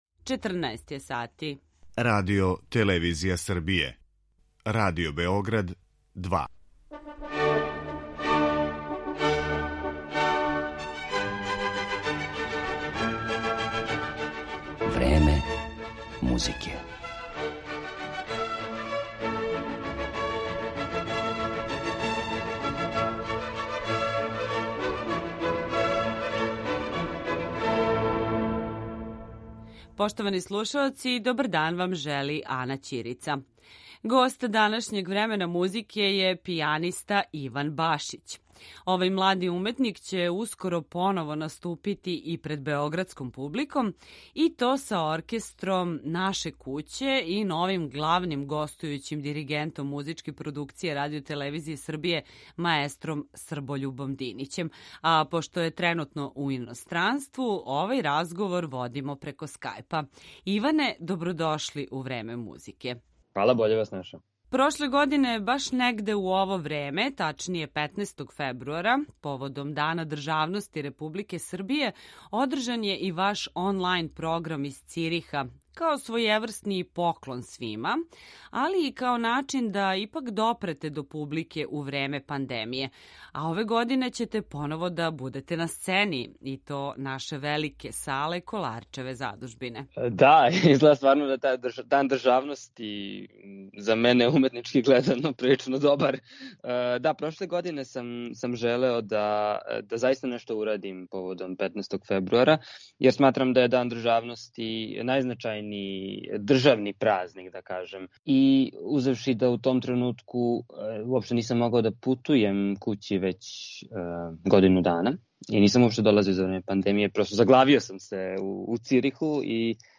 Пијаниста